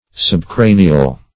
subcranial - definition of subcranial - synonyms, pronunciation, spelling from Free Dictionary
Search Result for " subcranial" : The Collaborative International Dictionary of English v.0.48: Subcranial \Sub*cra"ni*al\, a. (Anat.) Situated under, or on the ventral side of, the cranium; facial.